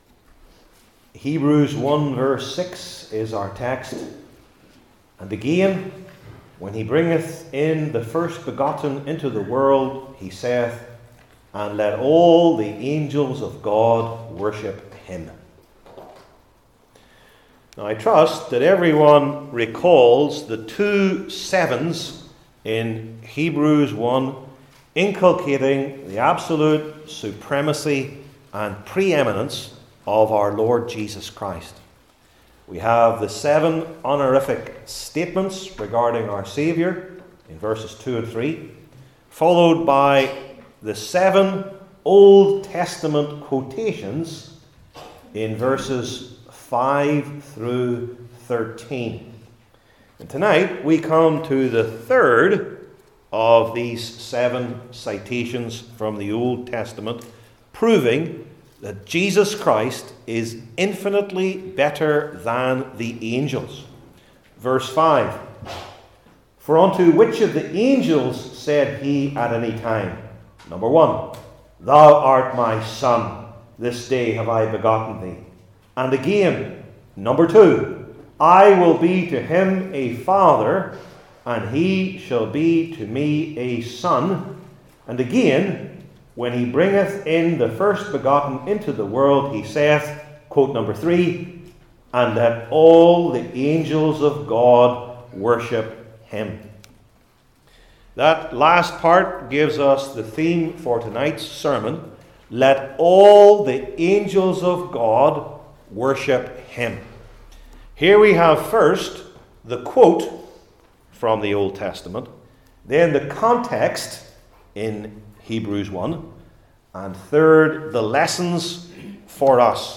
New Testament Sermon Series I. The Quote